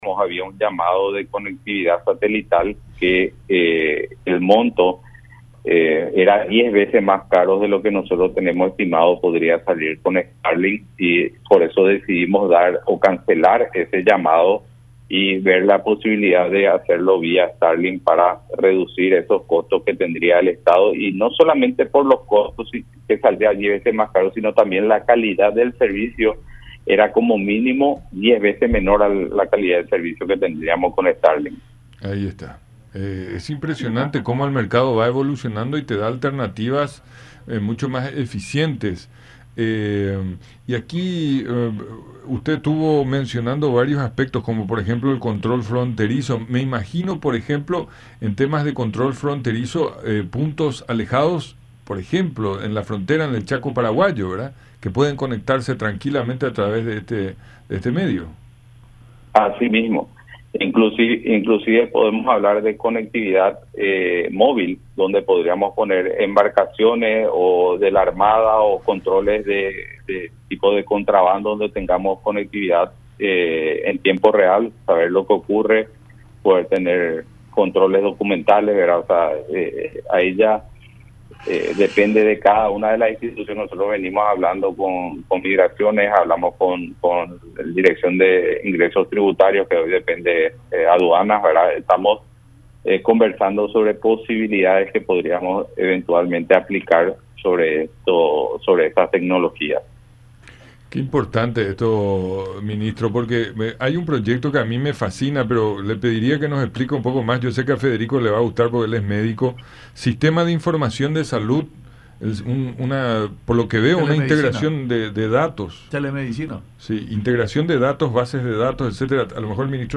Viene para complementar la fibra óptica”, agregó en el programa “Francamente” por radio La Unión y Unión Tv.